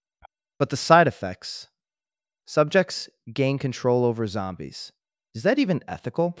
Add generated VibeVoice audio assets, dialogue JSON, and updated PrologueScene